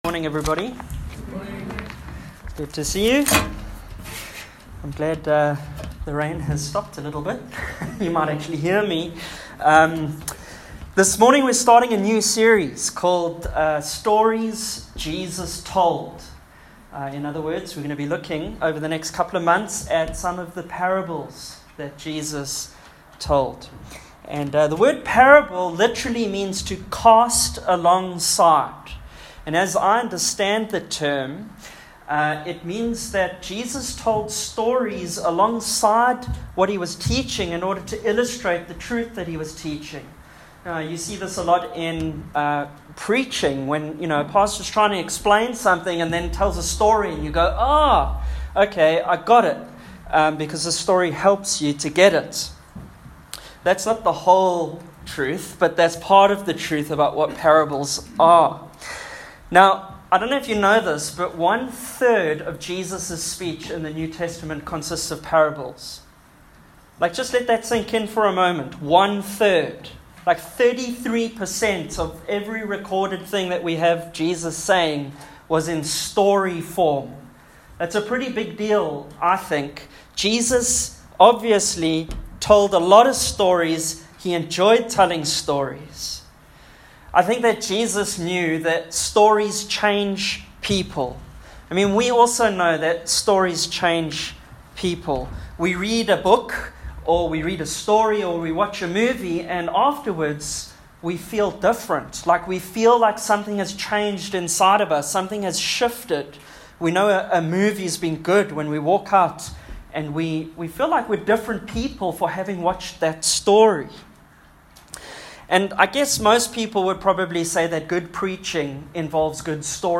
Passage: Luke 10:25-37 Service Type: AM